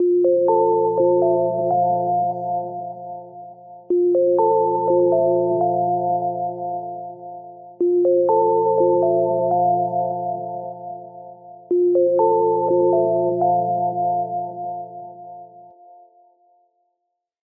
16. gentle bells